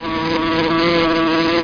BEEWAV.mp3